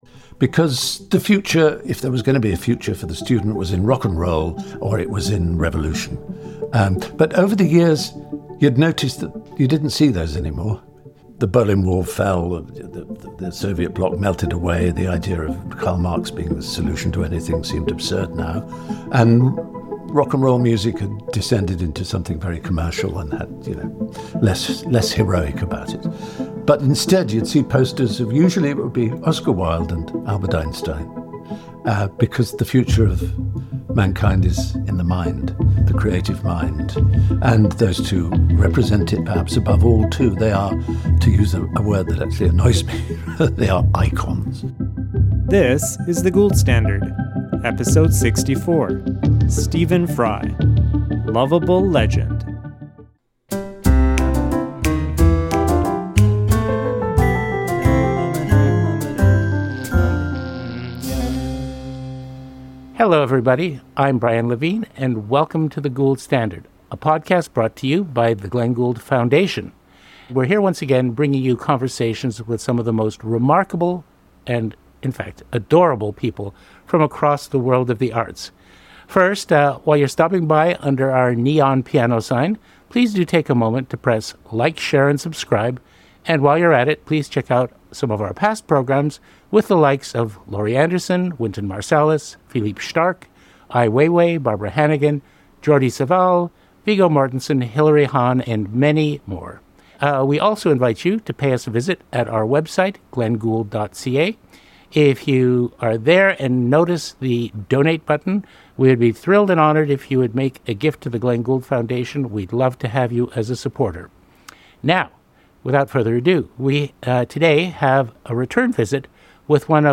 Don't miss this captivating conversation filled with humor, wisdom, and a call to cherish and safeguard human ingenuity.